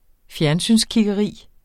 Udtale [ ˈfjæɐ̯nˌsyns- ]